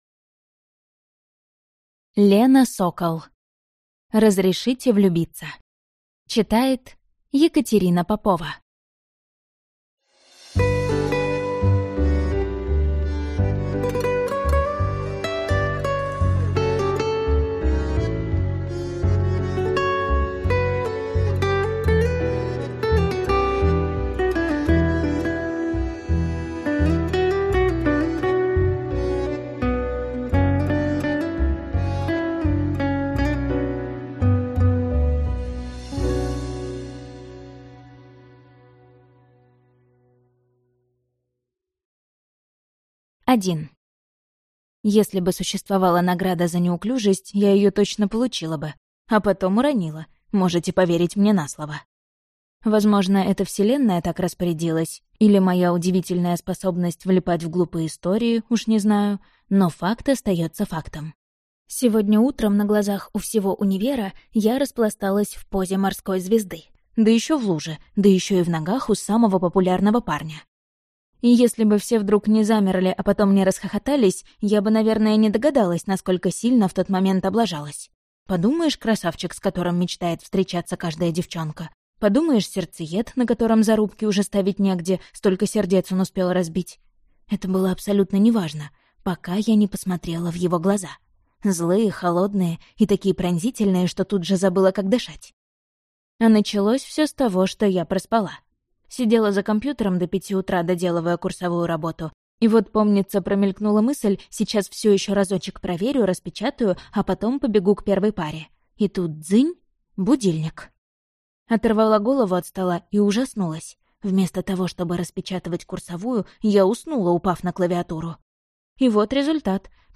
Аудиокнига Разрешите влюбиться | Библиотека аудиокниг